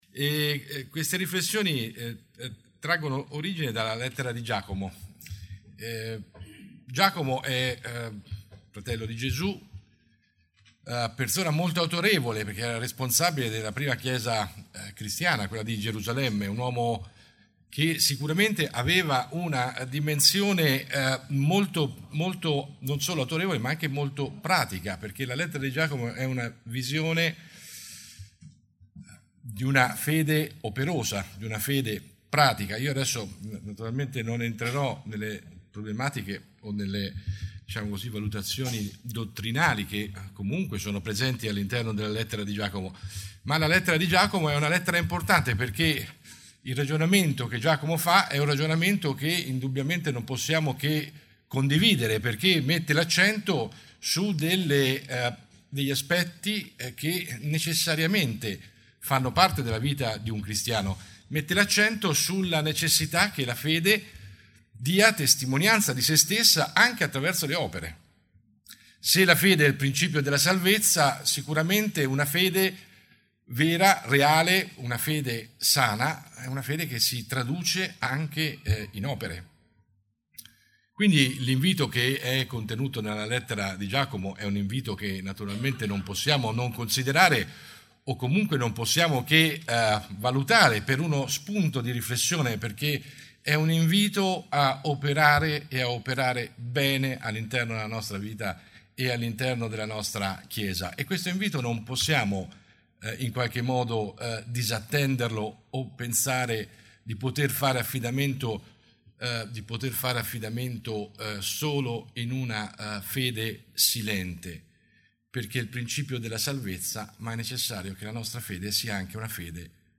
Tipo Di Incontro: Domenica